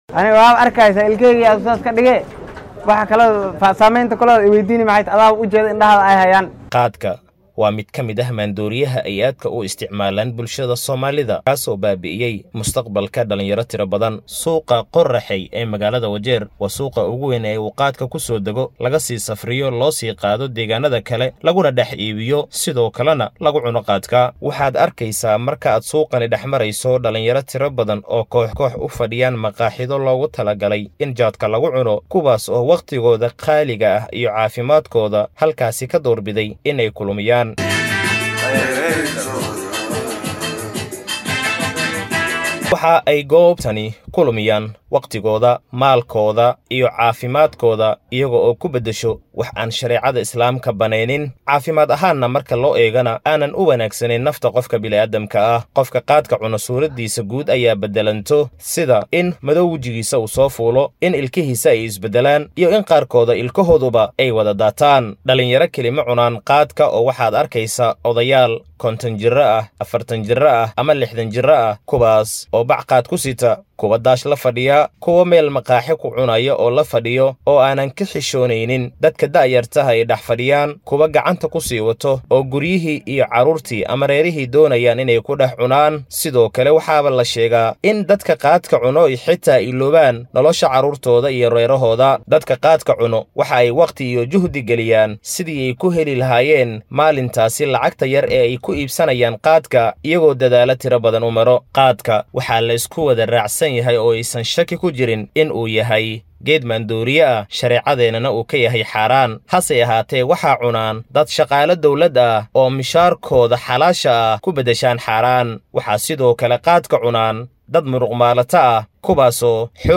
DHAGEYSO:Warbixin: Saameynta uu khaadka ku yeeshay dhallinyarada Wajeer